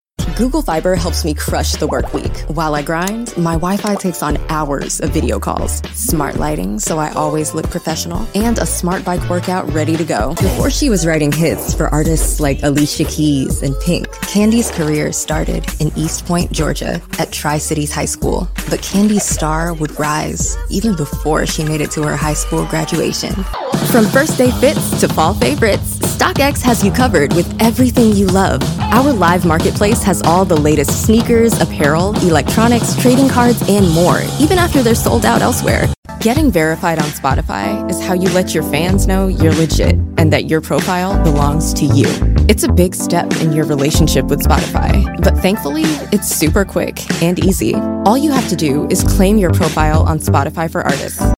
English - USA and Canada
Young Adult
Commercial